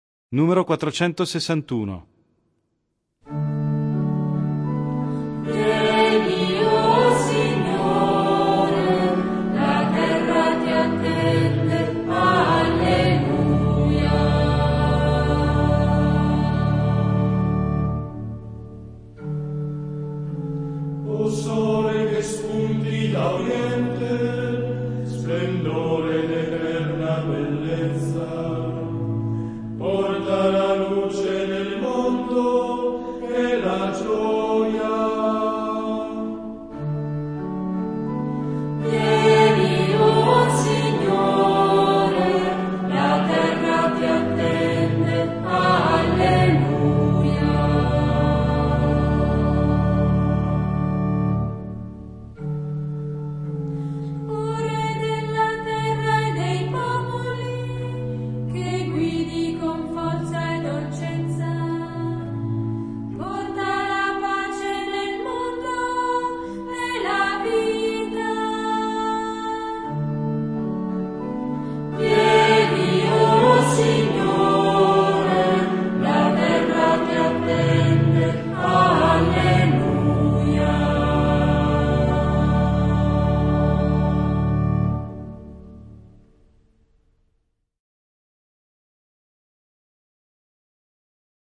Il canto di avvento prepara, come in un cammino, l'avvicinarsi al Natale: ecco allora che i tempi musicali sono quasi tutti in 3/4 o 6/8, come a mimare quel passo che ci accompagna verso il mistero: canti di meditazione e di incontro con la Parola che salva: non ci sarà più il Gloria, ma daremo spazio al canto della misericordia di Dio, invocando Kyrie, eleison!